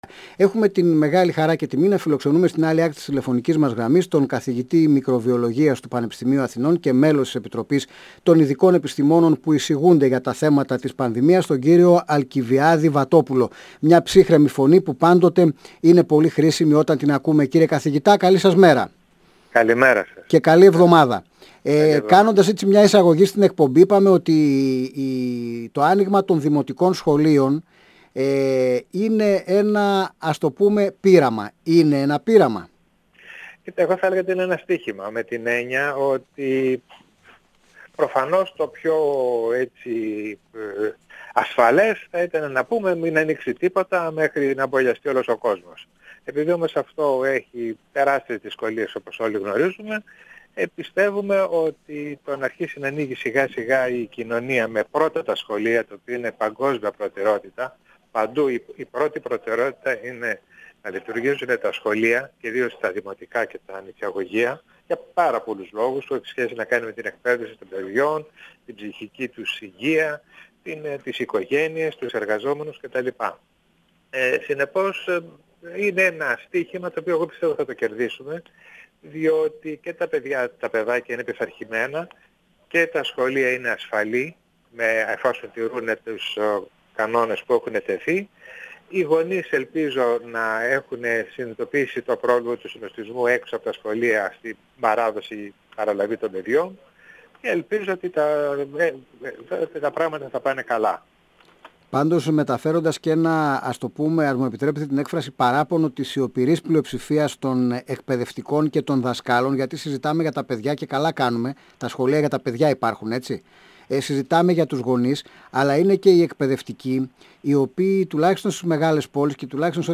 102FM Συνεντεύξεις